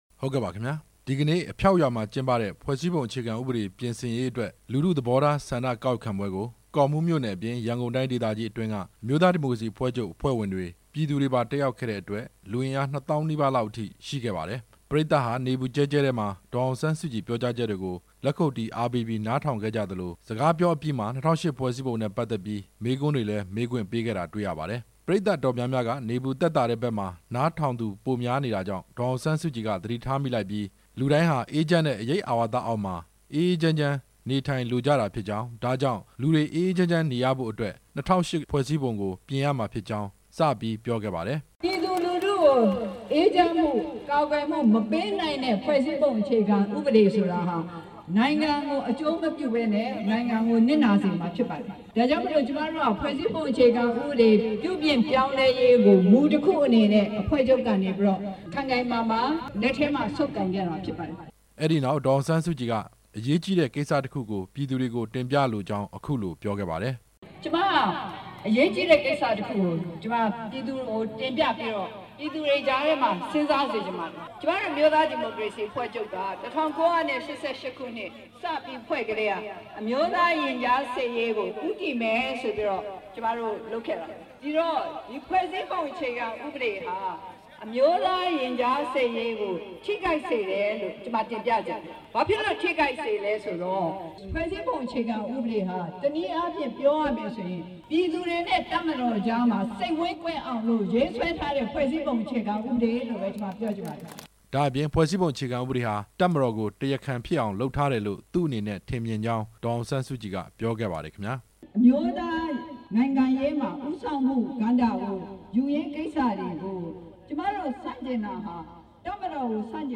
ဒေါ်အောင်ဆန်းစုကြည်ရဲ့ ပြောကြားချက်များကို ကောက်နှုတ်ချက်
ဒီနေ့ ရန်ကုန်တိုင်းဒေသကြီး ကောမှူးမြို့နယ် အဖျောက်ကျေးရွာမှာကျင်းပတဲ့ ၂၀၀၈ ဖွဲ့စည်းပုံ အခြေခံဥပဒေကို ပြင်ဆင်မလား အသစ်ပြန်လည်ရေးဆွဲမလား လူထုဆန္ဒကောက်ခံပွဲမှာ ဒေါ်အောင်ဆန်းစုကြည် မိန့်ခွန်းပြောကြားစဉ် ထည့်သွင်းပြောခဲ့တာပါ။